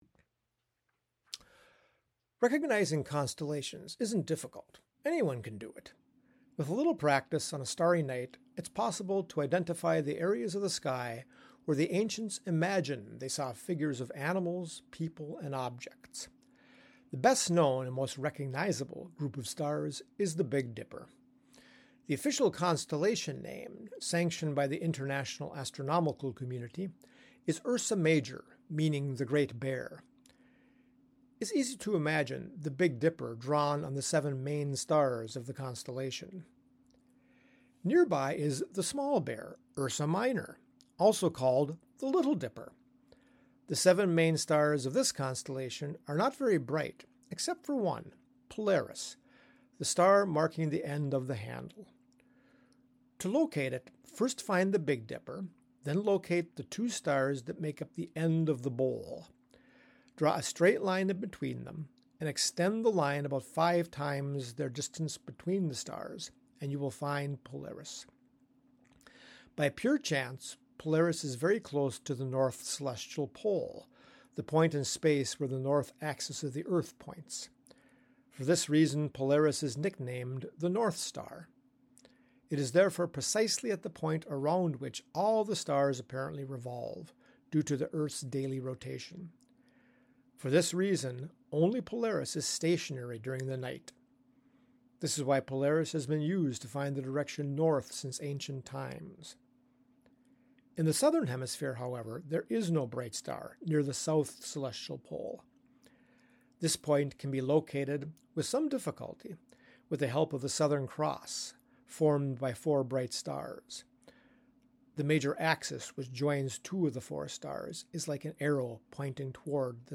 Il progetto “Idiomi celesti” promuove l’osservazione ad occhio nudo del cielo stellato con testi scritti e letti ad alta voce in tutte le lingue, dialetti compresi, come ad esempio la lingua ladina e il dialetto lumezzanese.